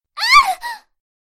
Female Startled Scream Sound Effect Free Download
Female Startled Scream